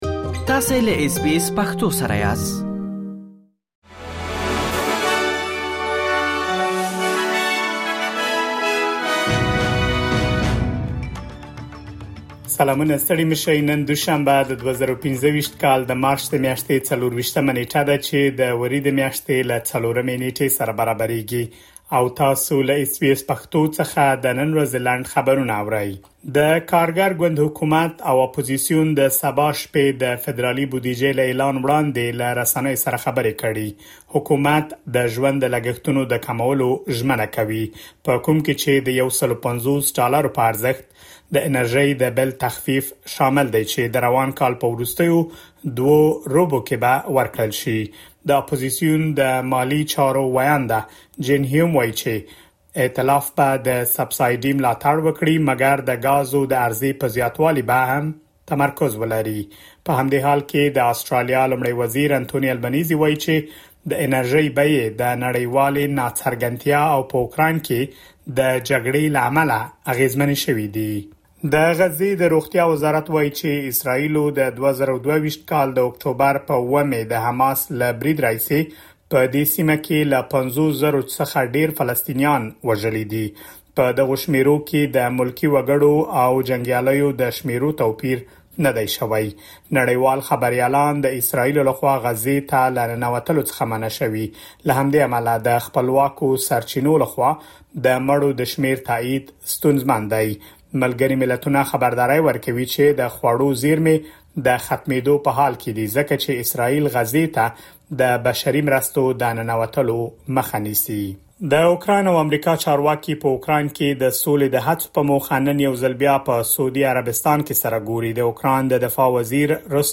د اس بي اس پښتو د نن ورځې لنډ خبرونه | ۲۴ مارچ ۲۰۲۵